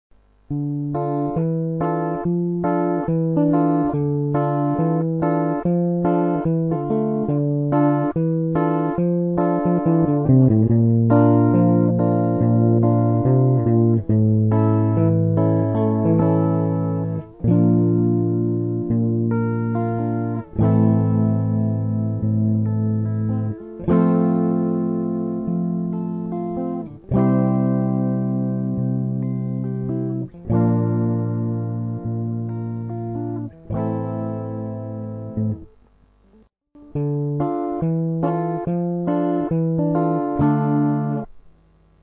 Вступление, проигрыш: Dm - B - A с таким басом:
mp3 - проигрыш и куплет